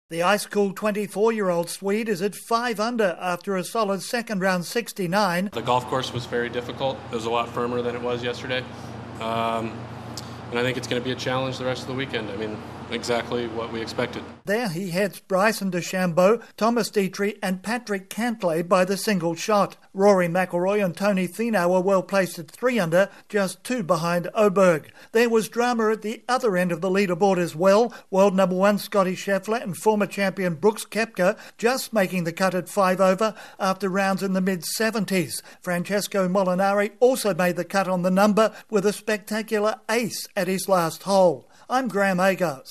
Ludvig Aberg holds a one shot lead at the half-way mark of the US Open championship at Pinehurst in North Carolina. ((NOTE pronunciation of Aberg is OH'-burg))